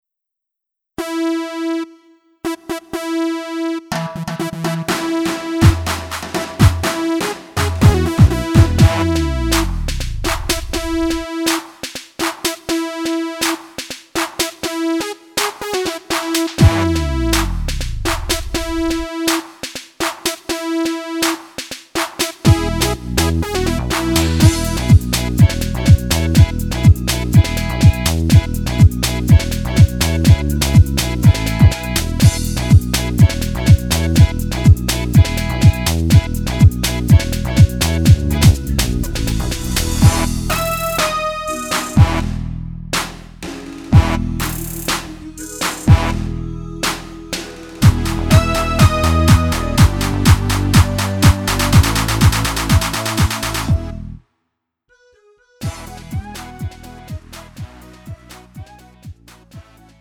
음정 원키 장르 가요
Lite MR